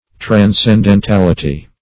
transcendentality - definition of transcendentality - synonyms, pronunciation, spelling from Free Dictionary
Search Result for " transcendentality" : The Collaborative International Dictionary of English v.0.48: Transcendentality \Tran`scen*den*tal"i*ty\, n. The quality or state of being transcendental.